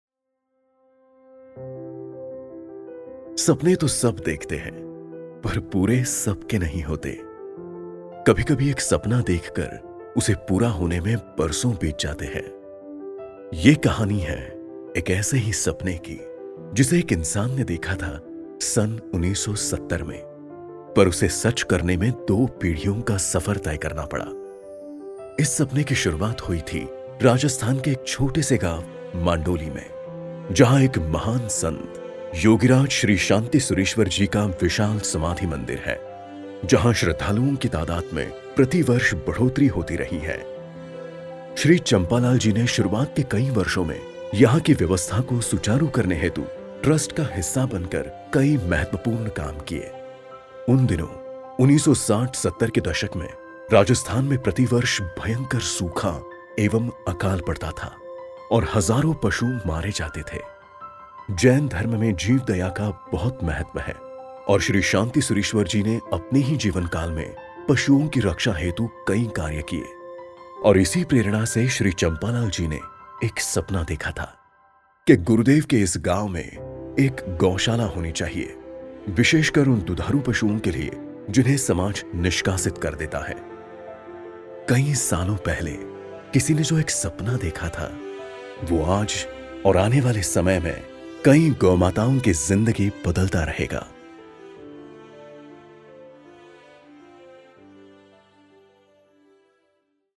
Hindi Documentary
Whenever you need a deep, baritone, sonorous voice to show your product to the world, you can get all of it from my voice.